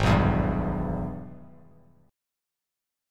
AbmM7#5 Chord
Listen to AbmM7#5 strummed